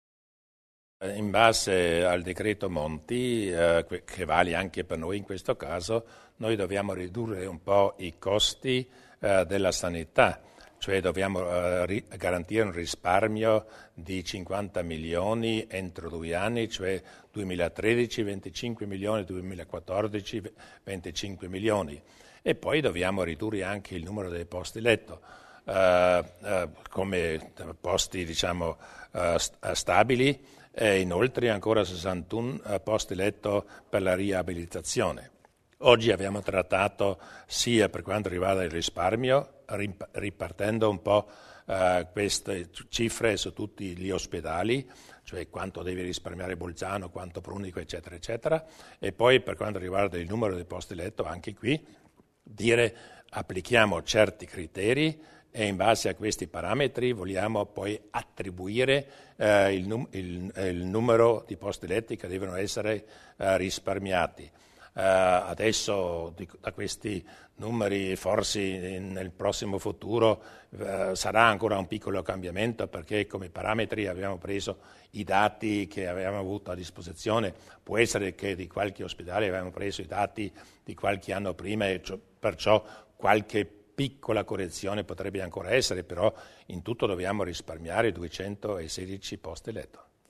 Il Presidente Durnwalder spiega gli interventi di riduzione della spesa nel settore sanitario